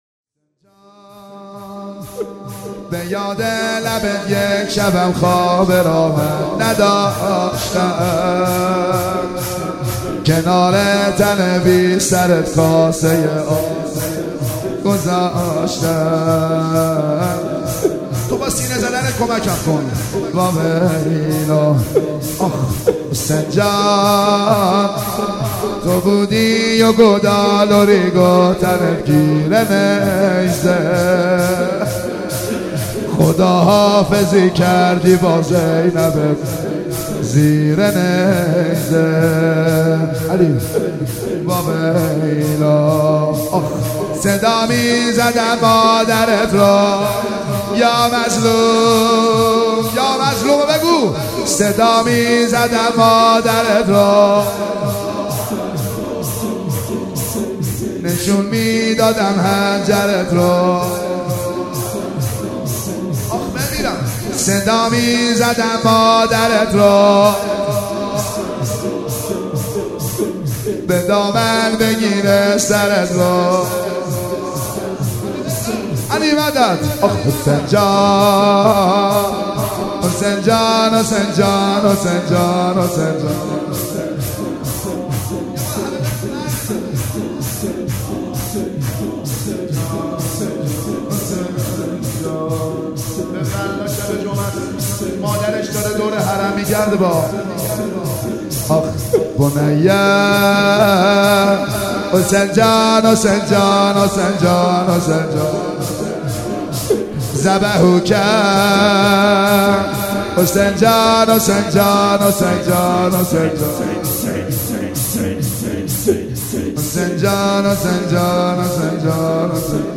گزارش تصویری مراسم
مداحی
موکب الشهدا ساوجبلاغ